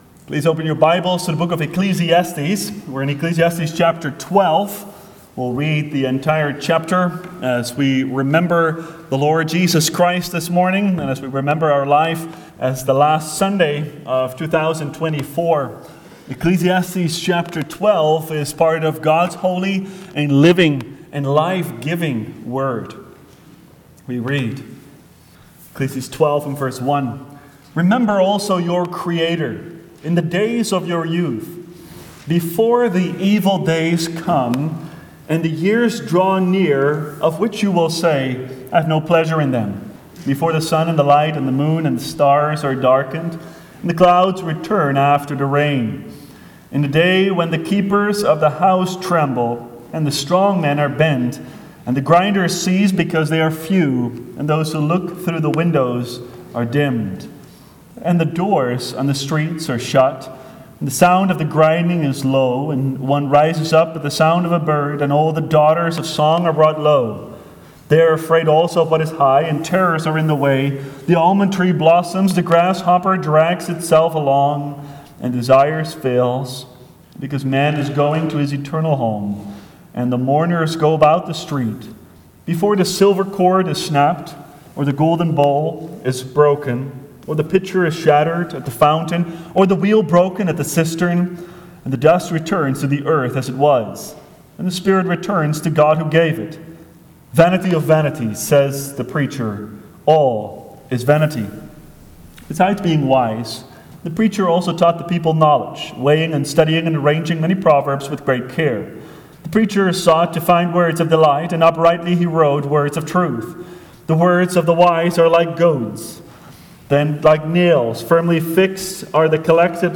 Judgement Series Preparatory and Communion Sermons Book Ecclesiastes Watch Listen Save In Ecclesiastes 12:1-14, we consider the brevity of life and our Creator as we reflect on the past year.